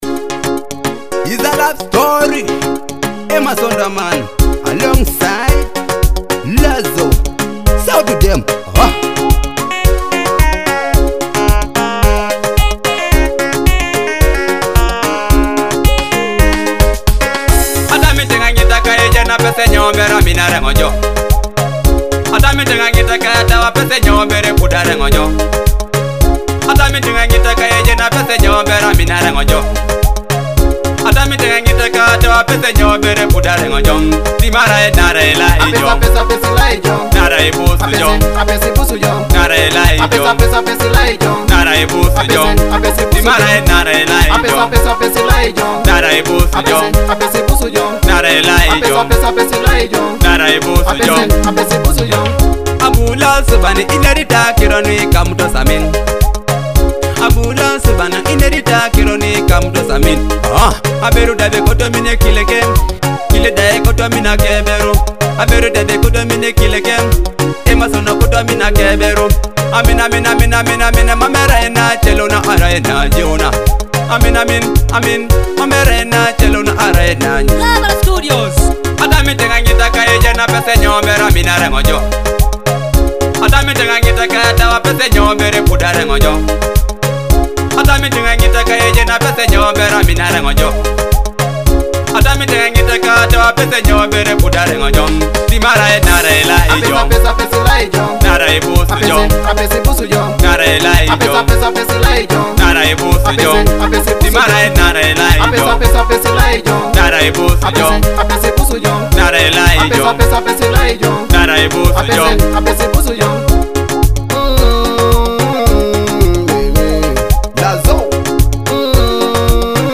a vibrant new track with powerful energy and feel.
Melancholic and emotive vocals
love dancehall with pulsing beat